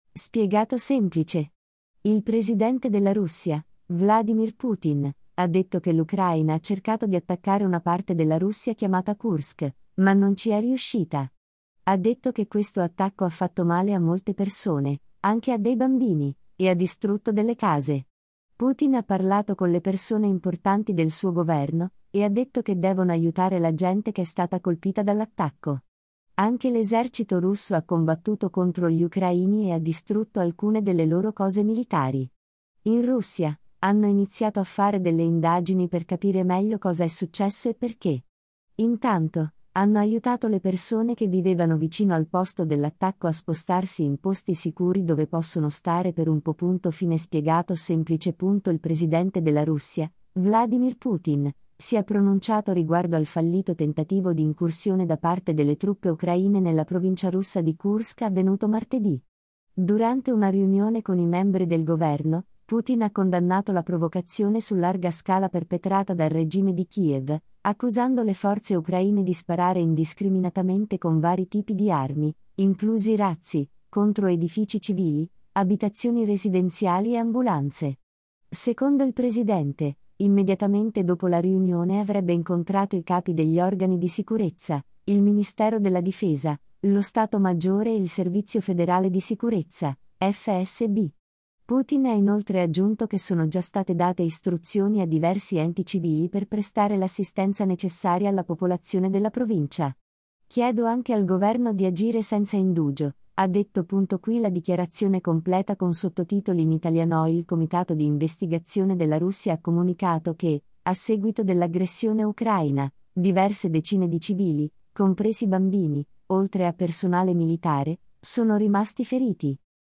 Durante una riunione con i membri del governo, Putin ha condannato la “provocazione su larga scala” perpetrata dal regime di Kiev, accusando le forze ucraine di sparare indiscriminatamente con vari tipi di armi, inclusi razzi, contro edifici civili, abitazioni residenziali e ambulanze.
QUI LA DICHIARAZIONE COMPLETA CON SOTTOTITOLI IN ITALIANO